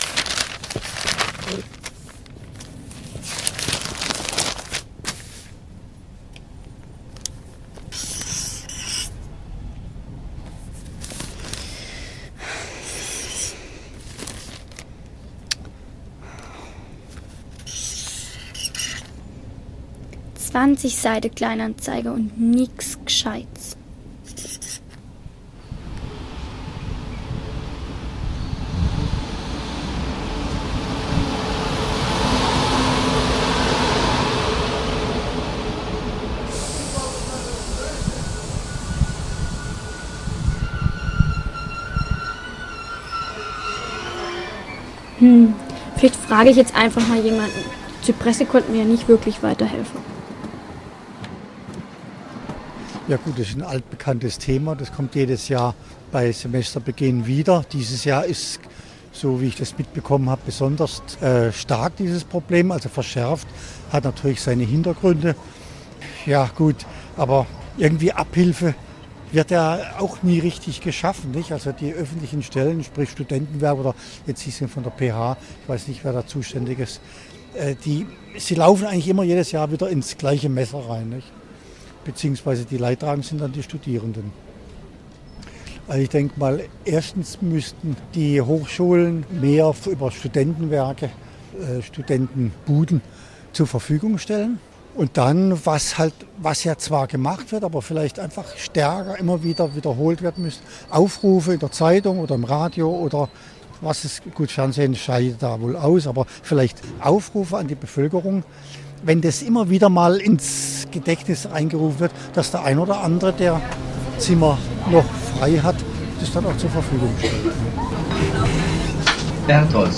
Dieser Eintrag wurde veröffentlicht unter Hörspiel Podcast-Archiv der PH-Freiburg und verschlagwortet mit deutsch am von
So drastisch wie dieses Jahr war die Knappheit noch selten. Dies erfuhren Studierende der Pädagogischen Hochschule: ein kleines Hörspiel mit Aufnahmen vom vergangenen Wochenende.